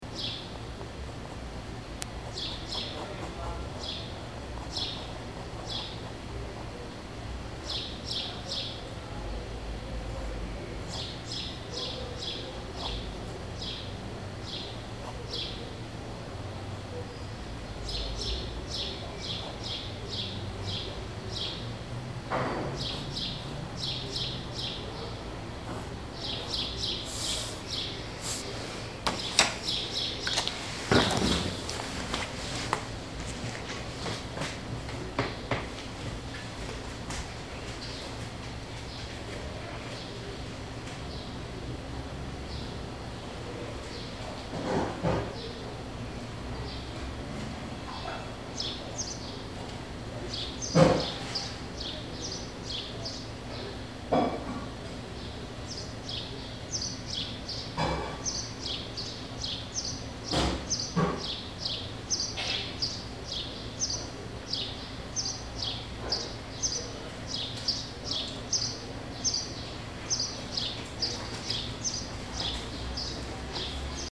L’ambiance est calme et détendue, on boit un Apfelsaftschorle bien frais.